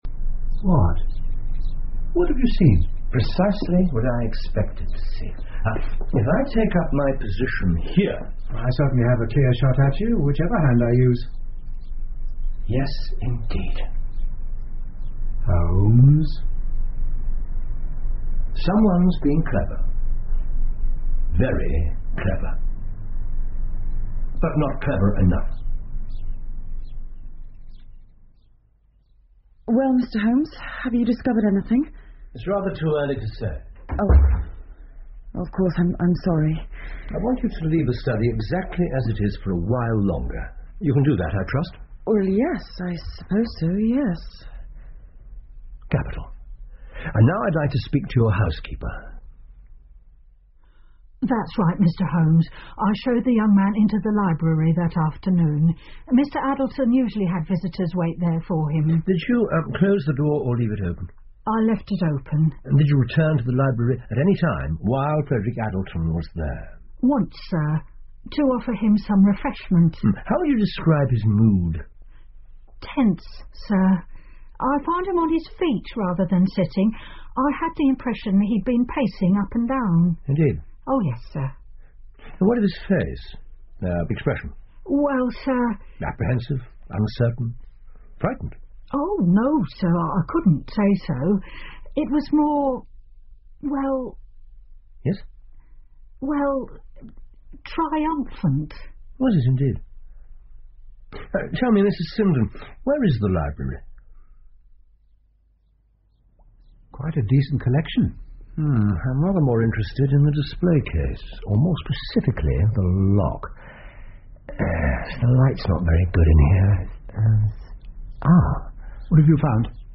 福尔摩斯广播剧 The Determined Client 5 听力文件下载—在线英语听力室